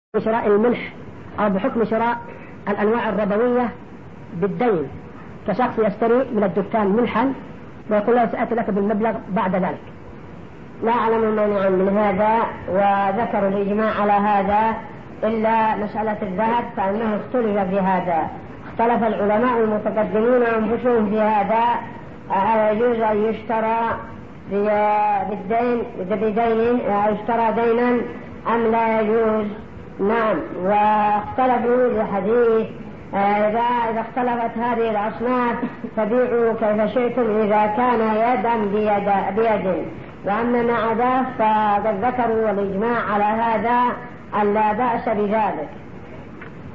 | فتاوى الشيخ مقبل بن هادي الوادعي رحمه الله